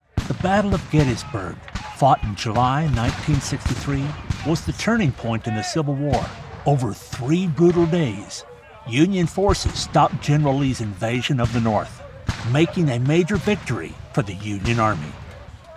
Male
English (North American)
Adult (30-50), Older Sound (50+)
0722history_elearning.mp3